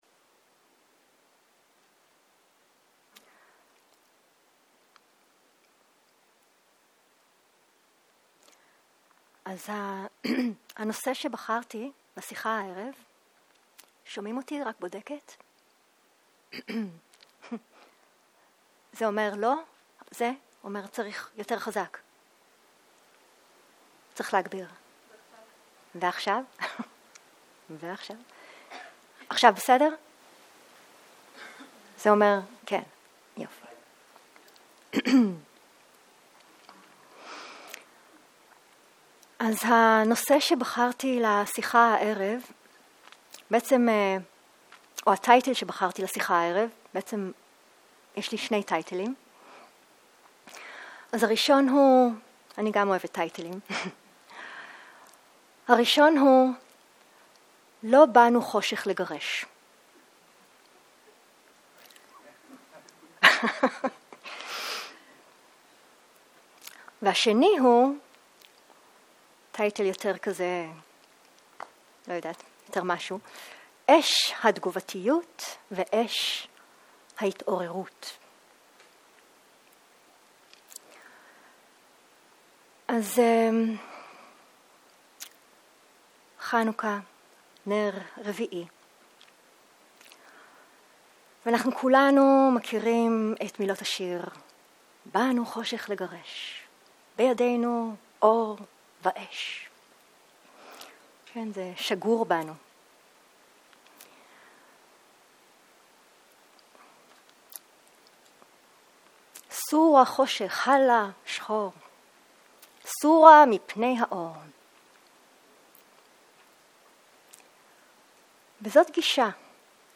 שיחת דהרמה - לא באנו חושך לגרש